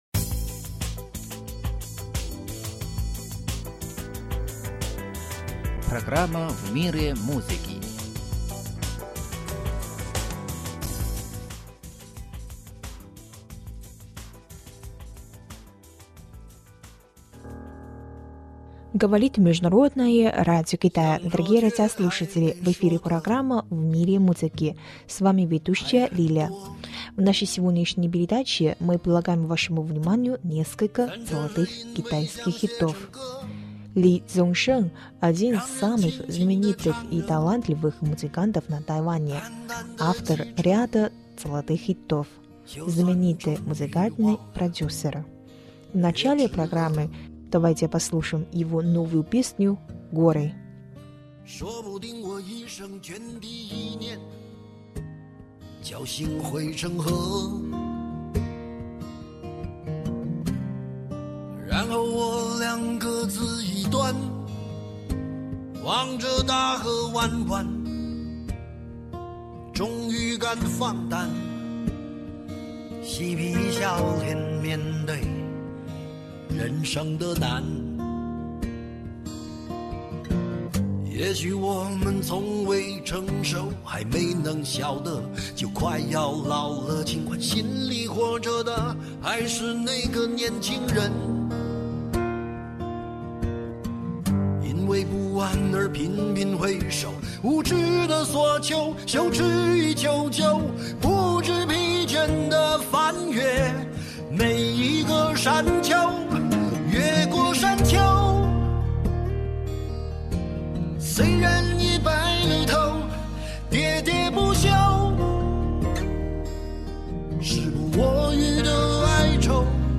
Дорогие радиослушатели, в эфире программа «В мире музыки». В нашей сегодняшней передаче мы предлагаем вашему вниманию несколько золотых китайских хитов.